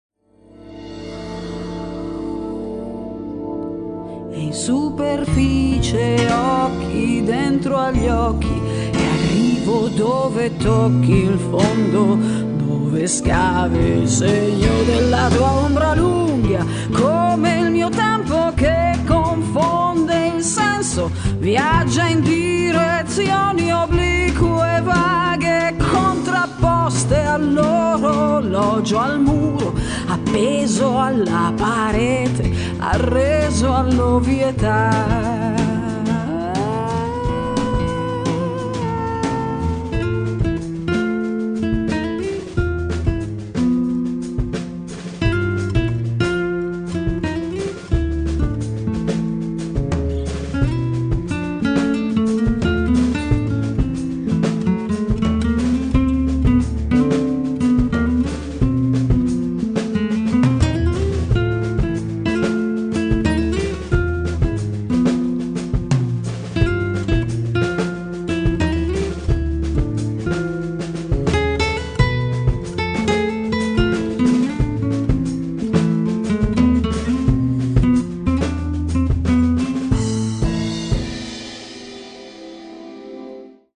chitarra e voce
pianoforte
contrabbasso
batteria